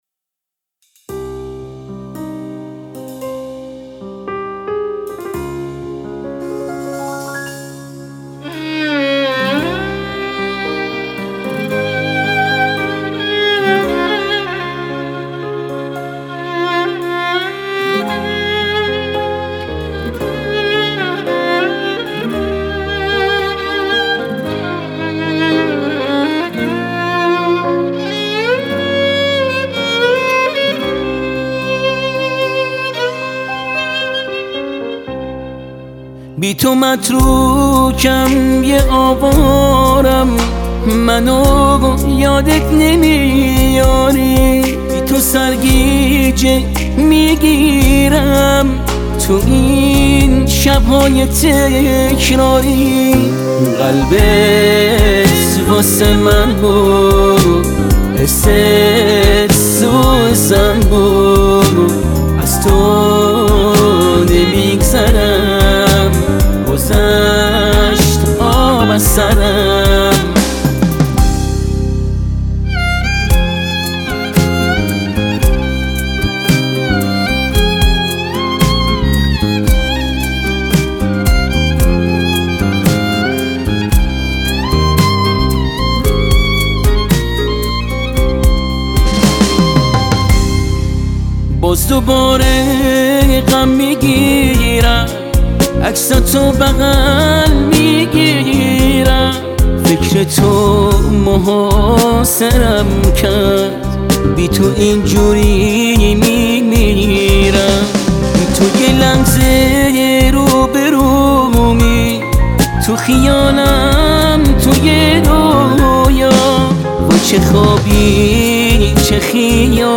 زیبا و احساسی